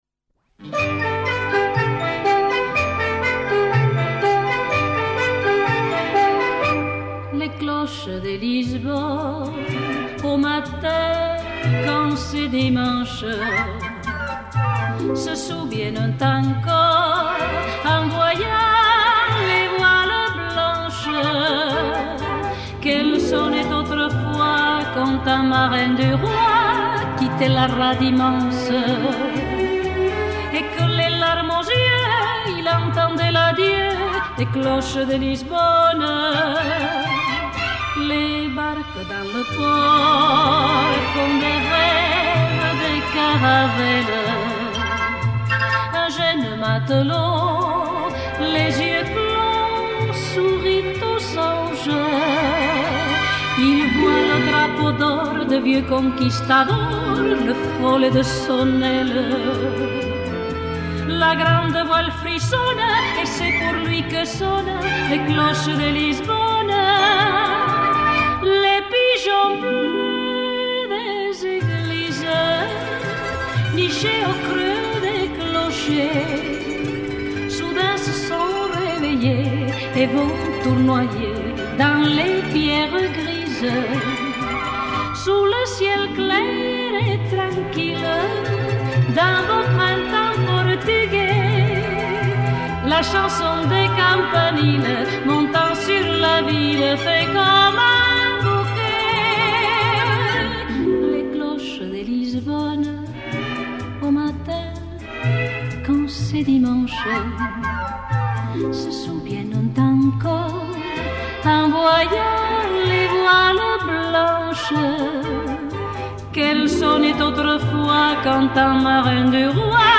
je ne pouvais que choisir un son de cloches
la belle voix
Vous vous souvenez tous de cette jolie chanson?